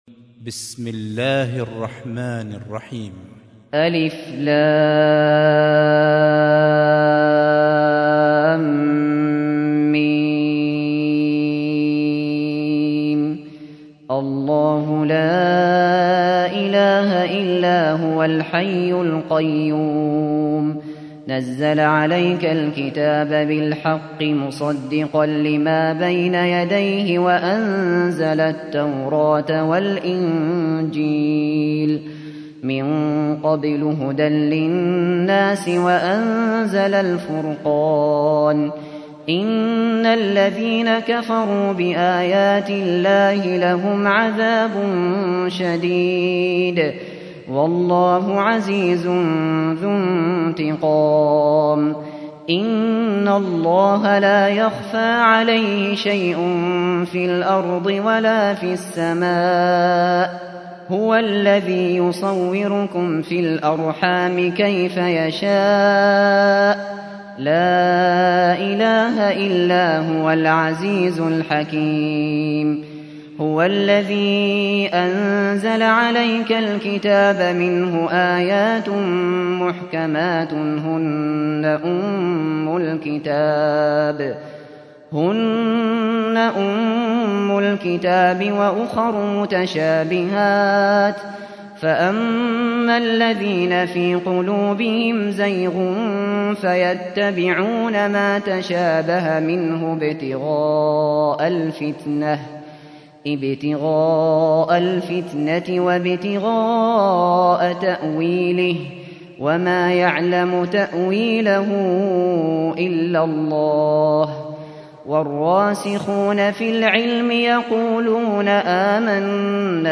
سُورَةُ ٓآلِ عِمۡرَانَ بصوت الشيخ ابو بكر الشاطري